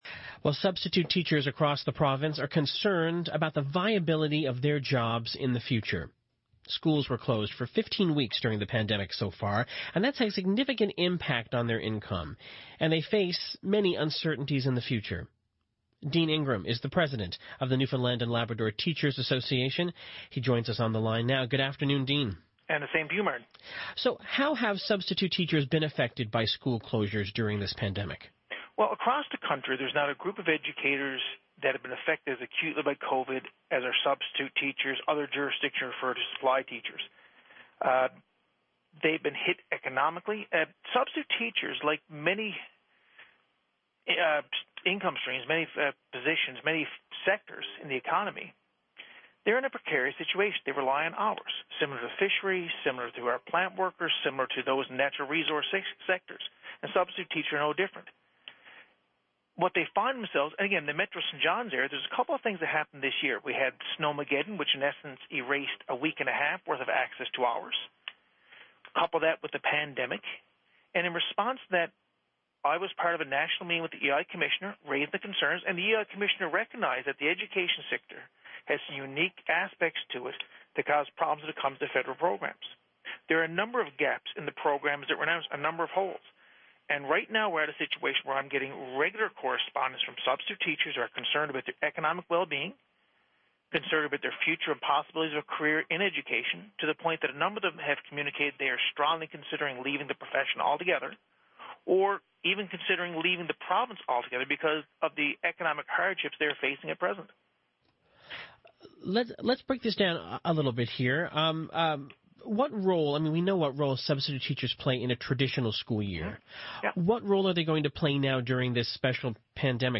Media Interview - CBC On the Go - July 28, 2020